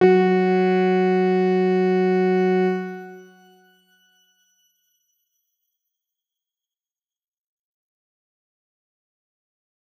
X_Grain-F#3-pp.wav